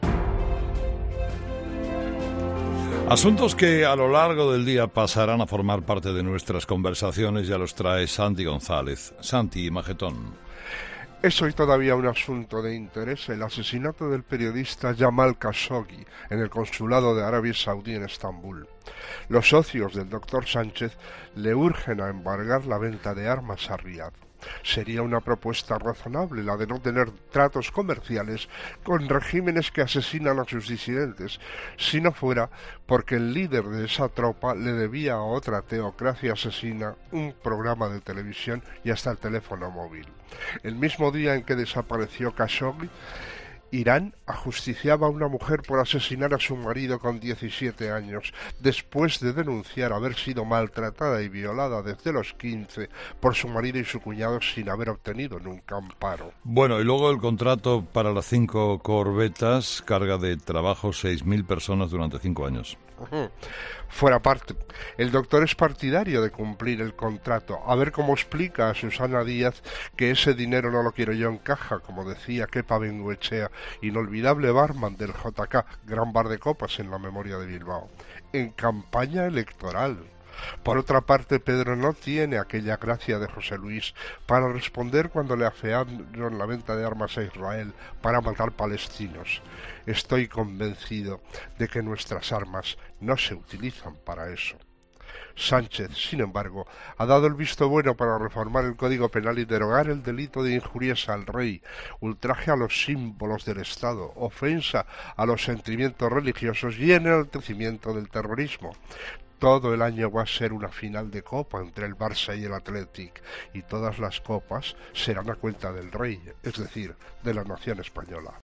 El comentario de Santiago González